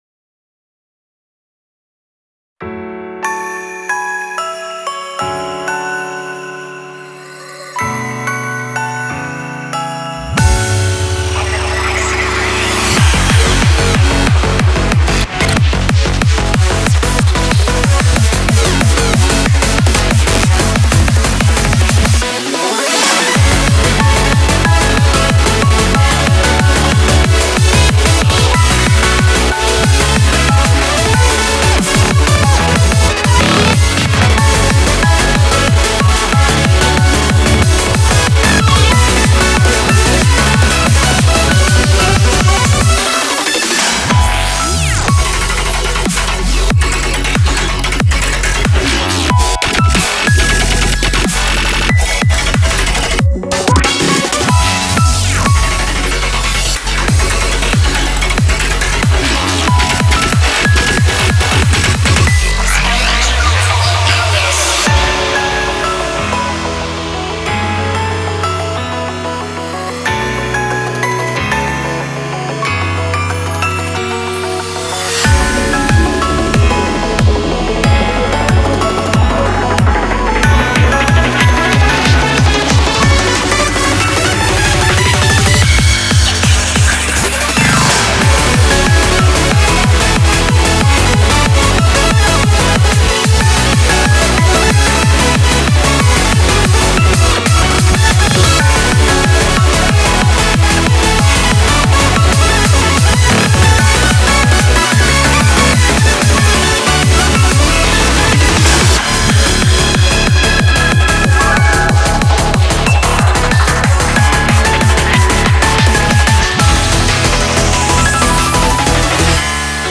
BPM93-370
Audio QualityPerfect (High Quality)
TIP: Main BPM for all difficulties is 185.